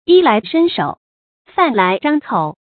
yī lái shēn shǒu，fàn lái zhāng kǒu
衣来伸手，饭来张口发音